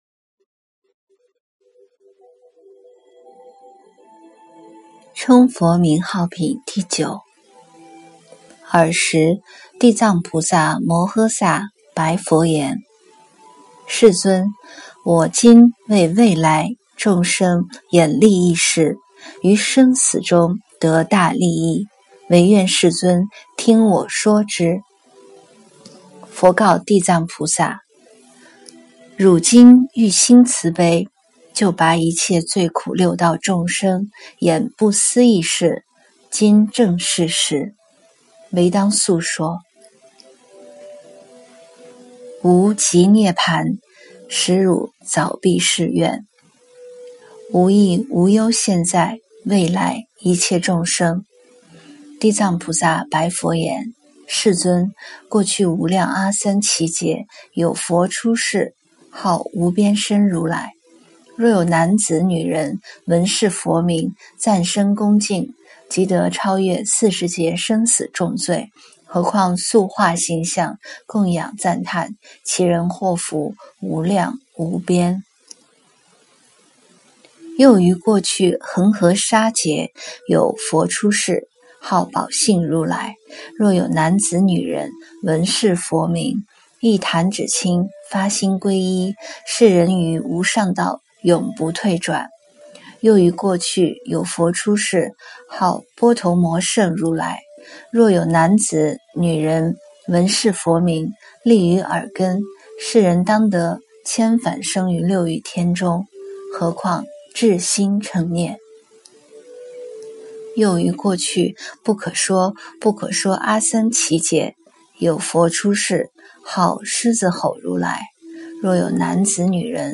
《地藏经》称佛名号品第九 - 诵经 - 云佛论坛